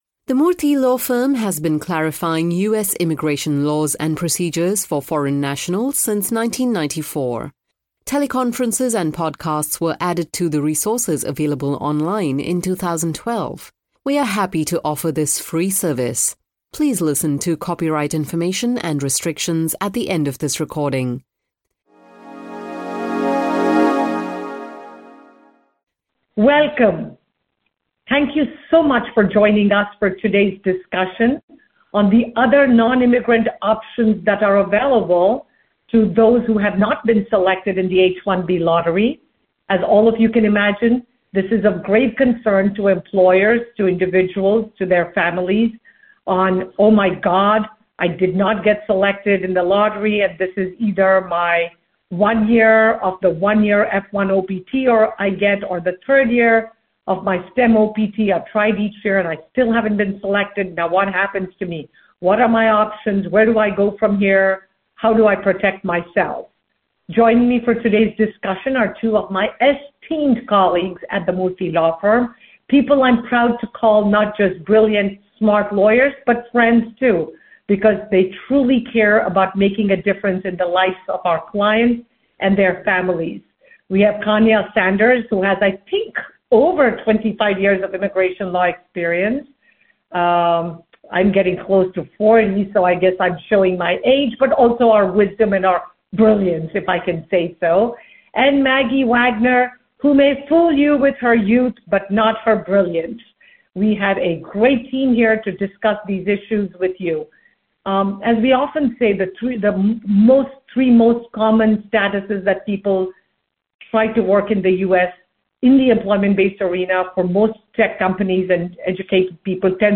Strategies for filing nonimmigrant status when H-1 and L-1 are not available options are discussed by Murthy Law Firm attorneys in this April 2025 podcast from our series recommended specifically for employers.